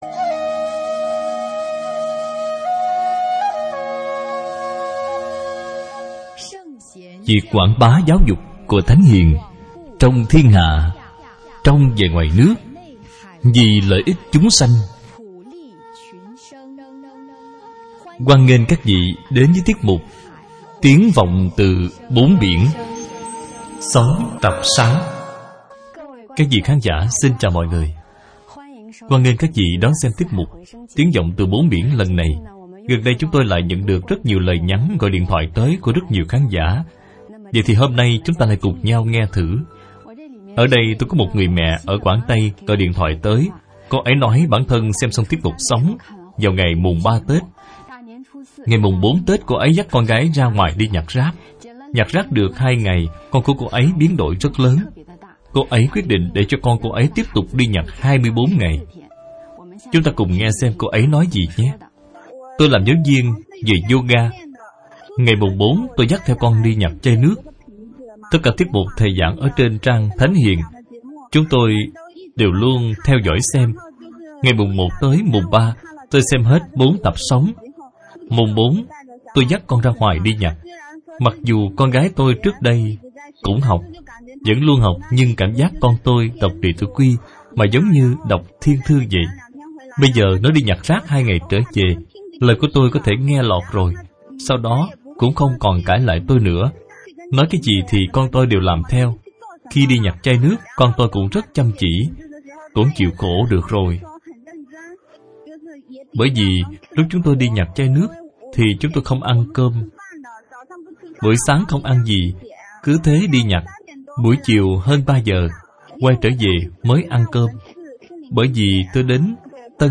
Sống - Tập 2 - Bài giảng Video